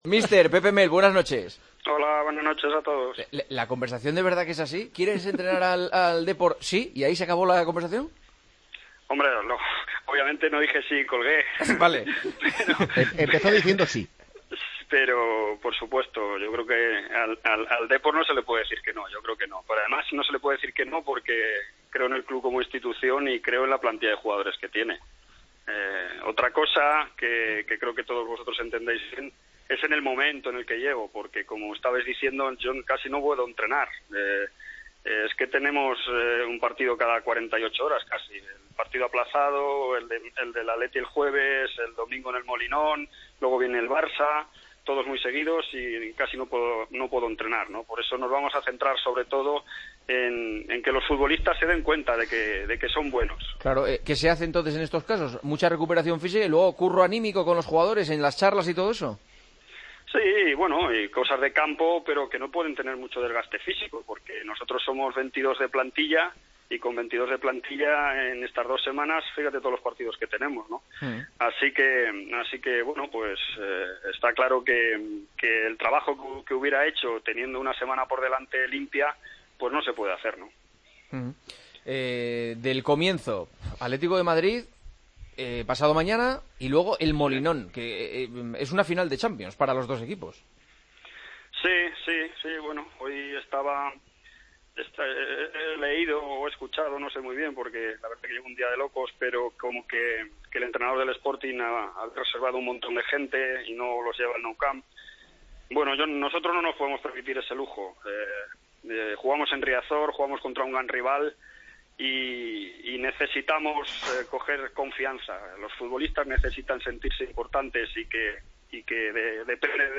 Hablamos con el nuevo entrenador del Deportivo de la Coruña, horas después de ser presentado por el conjunto gallego: